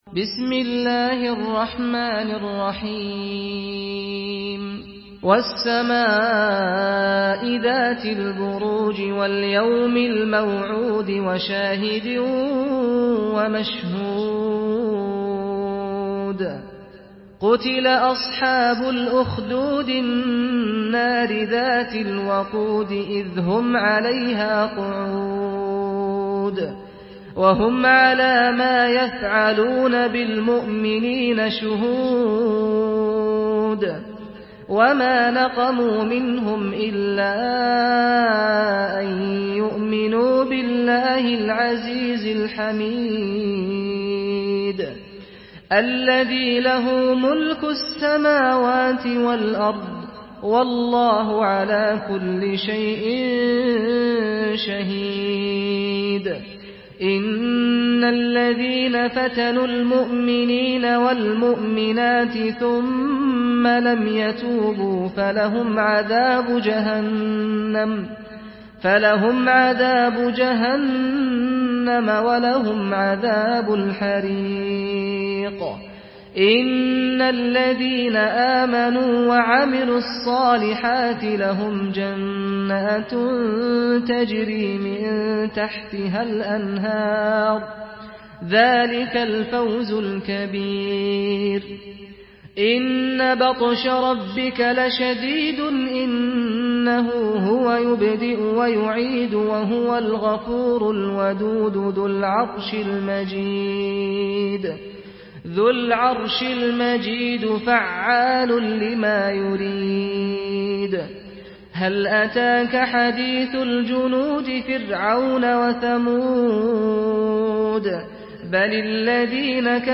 Surah Al-Buruj MP3 by Saad Al-Ghamdi in Hafs An Asim narration.
Murattal Hafs An Asim